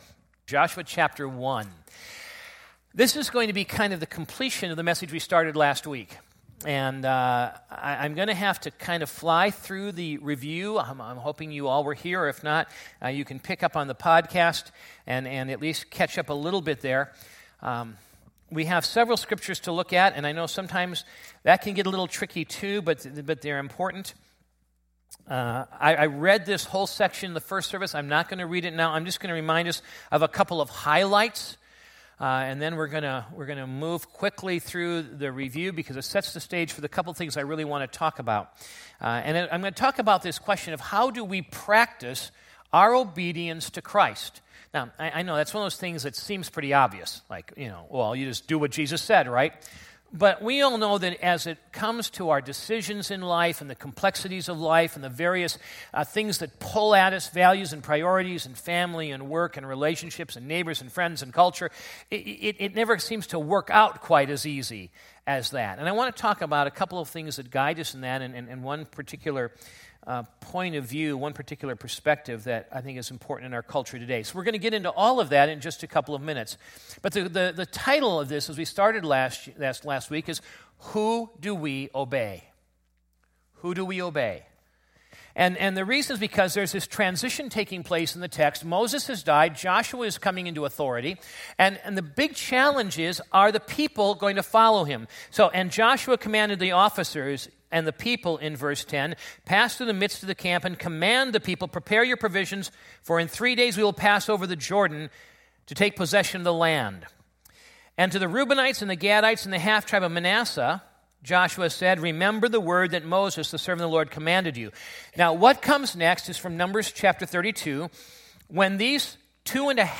Sunday Morning Message